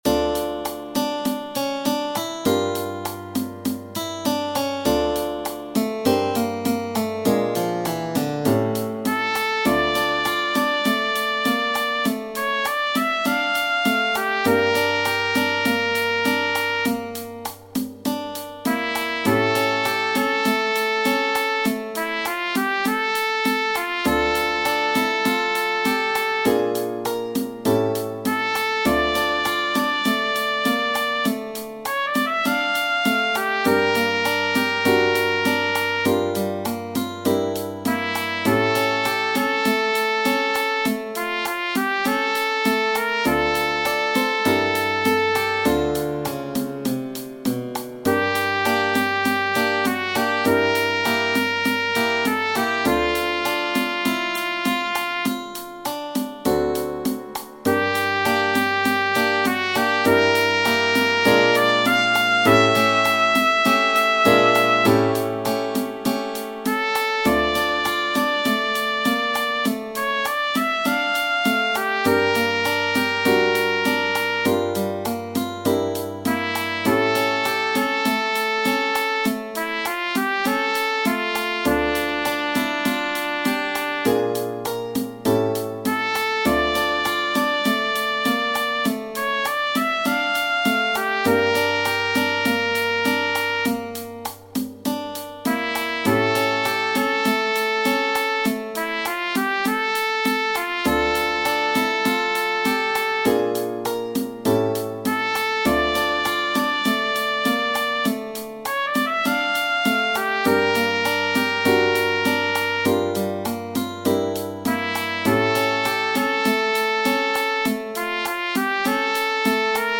bolero